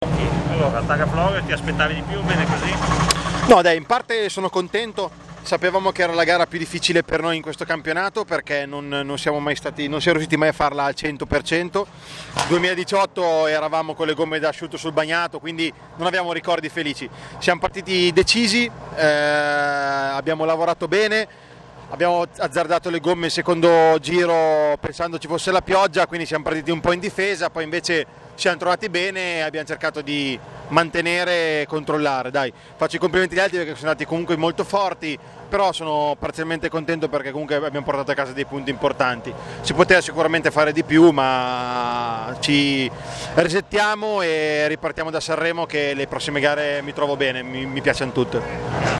Interviste Rally Targa Florio 2020
Interviste di fine rally